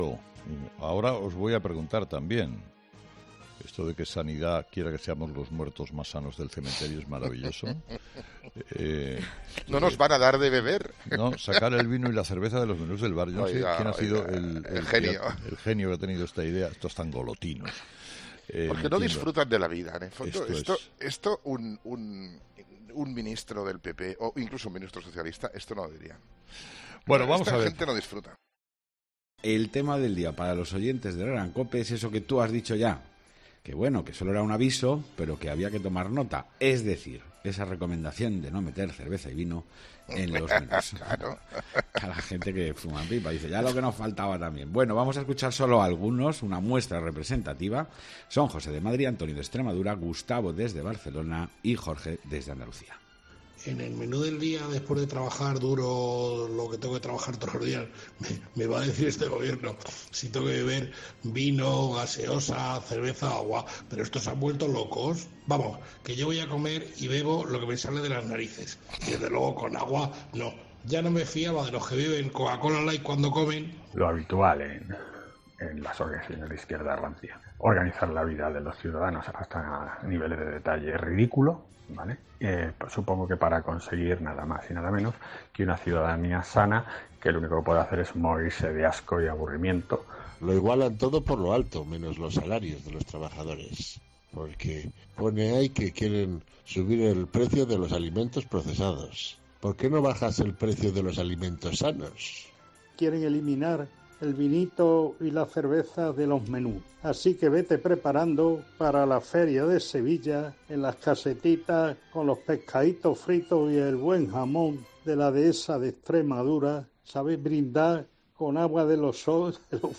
El presentador de 'Herrera en COPE' ha analizado las consecuencias que se pueden dar si la polémica propuesta de Sanidad llega a concretarse
En la sección de 'Herrera en COPE' en la que los oyentes toman protagonismo lo hemos podido comprobar, con un Carlos Herrera que, aunque irónico, se ha mostrado contrariado por la propuesta.